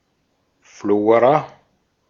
Ääntäminen
Synonyymit microflora Ääntäminen US Tuntematon aksentti: IPA : /ˈflɔː.rə/ Haettu sana löytyi näillä lähdekielillä: englanti Käännös Ääninäyte Substantiivit 1.